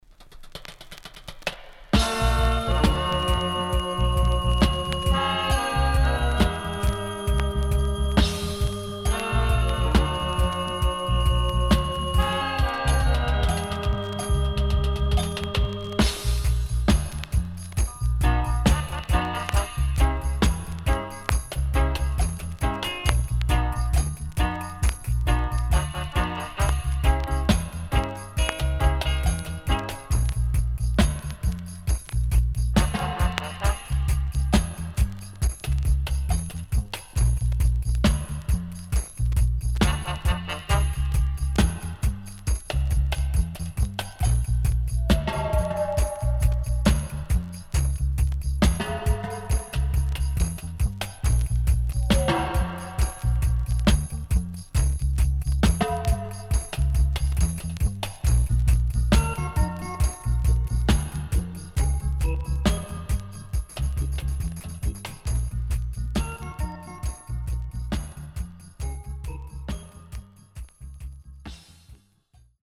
HOME > DUB